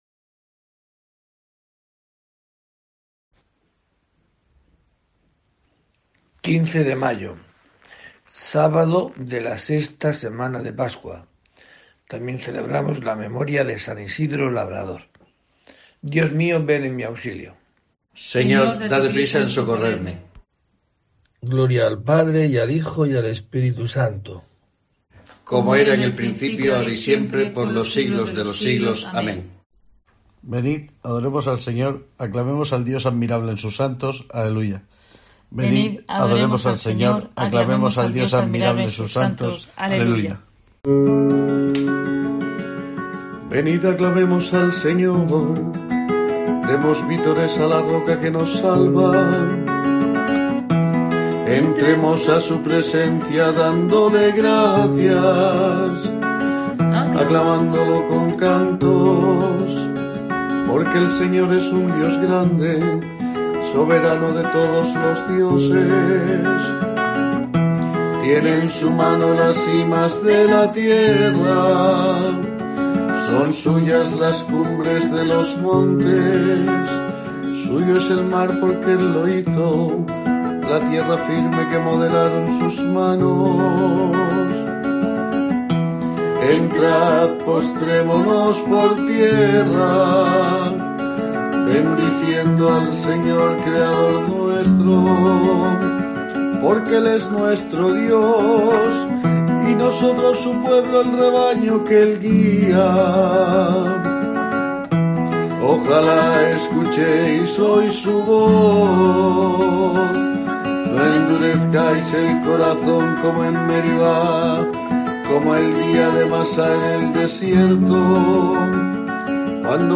15 de mayo: COPE te trae el rezo diario de los Laudes para acompañarte